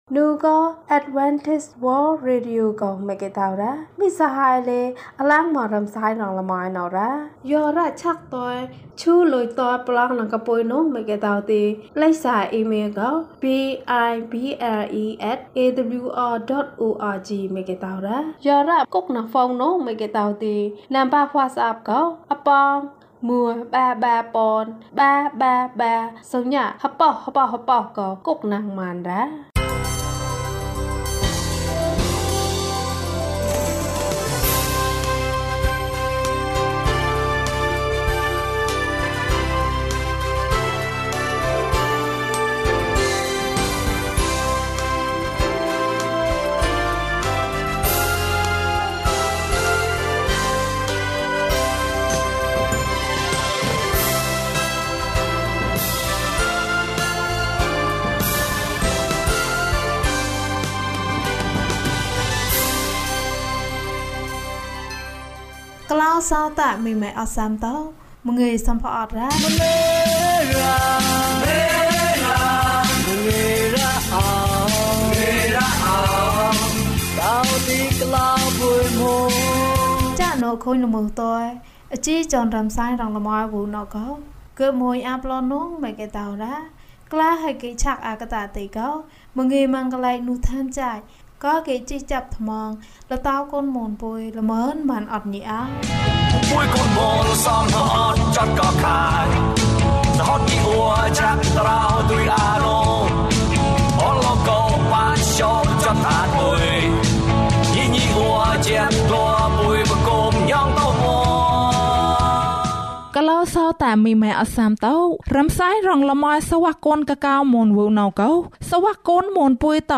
ဝတ်ပြုဆုတောင်းခြင်းနှင့် ကျန်းမာရေးဟောပြောပွဲ။ ဓမ္မသီချင်း။ တရားဒေသနာ။